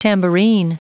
Prononciation du mot tambourine en anglais (fichier audio)